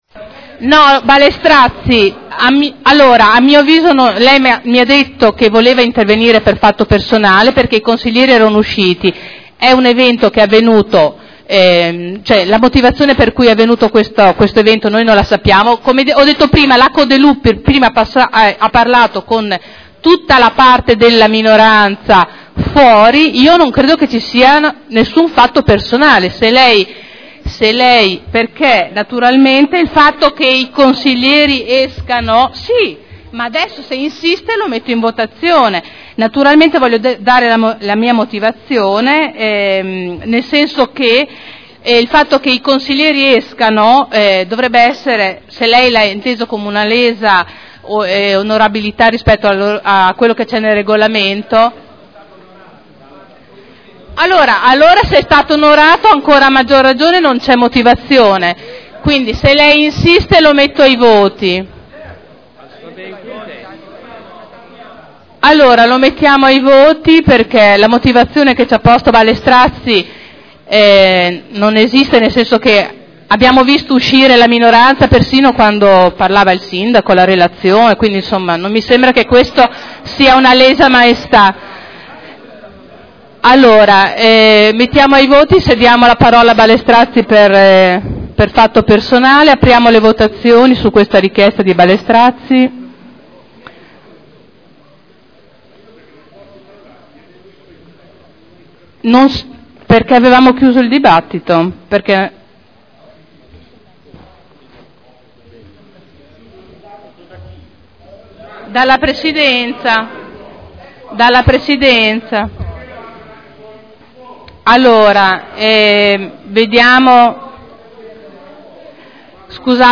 Seduta del 07/03/2011. Ordine del giorno avente per oggetto: “No alla “legge Bavaglio”, sì ad una seria e completa informazione e ad una giustizia imparziale” – Primo firmatario consigliere Trande Votazione su richiesta intervento di Ballestrazzi a dibattito chiuso